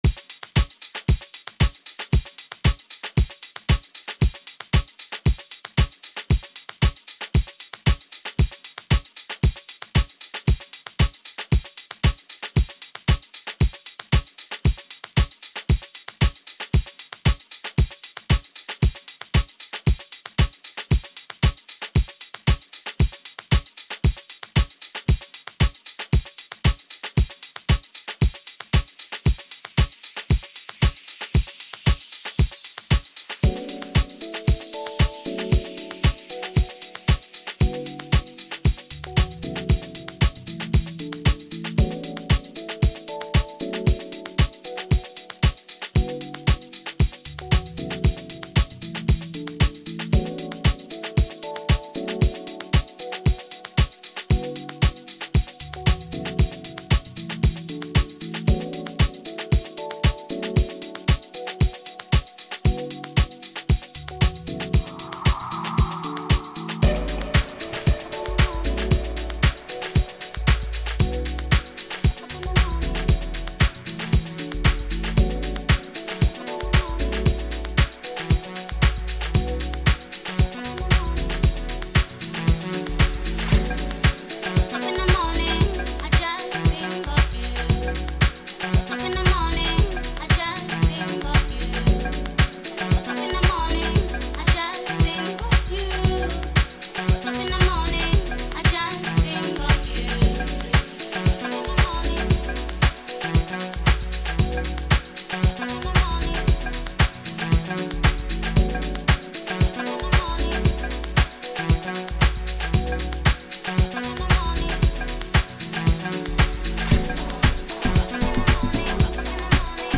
Home » Amapiano » DJ Mix » Hip Hop
South African singer